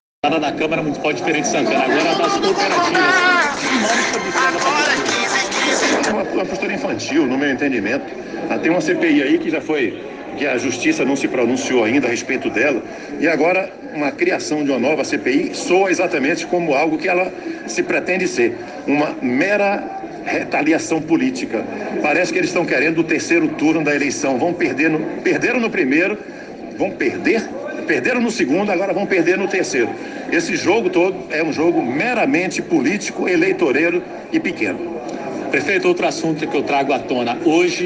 Durante entrevista coletiva na tarde de quinta-feira (17), o prefeito de Feira de Santana Colbert Martins MDB, acusou os vereadores de criar nova CPI (das Cooperativas) por retaliação política e classificou como criancice.